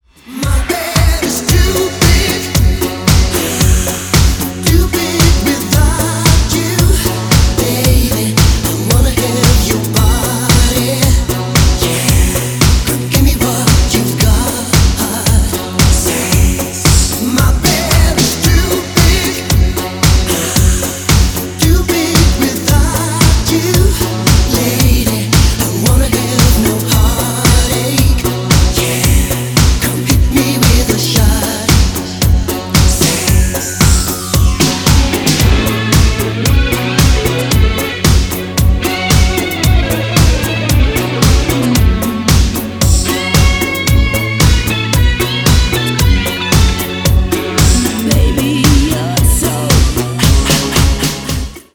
• Качество: 320, Stereo
мужской вокал
remix
Synth Pop
dance
Electronic
disco
ретро